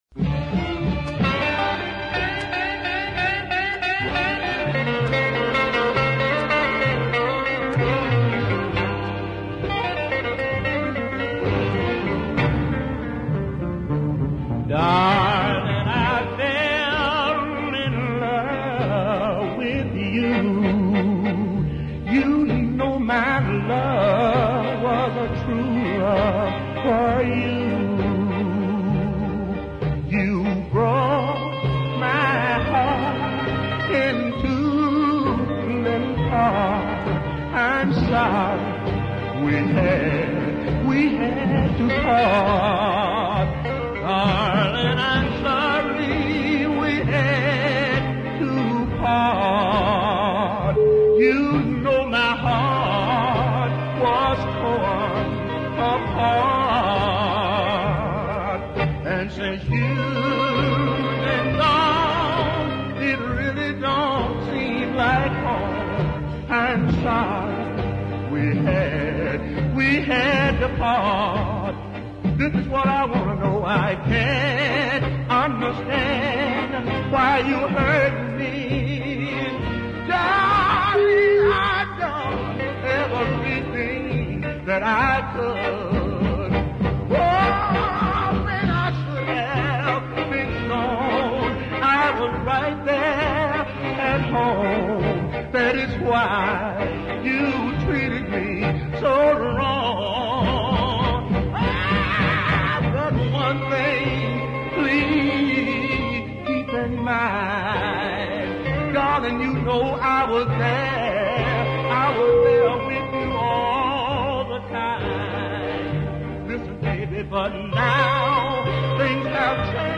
splendid blues ballad
The beautifully timed brass phrases and stinging lead guitar